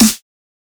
Snare 2 (Right In).wav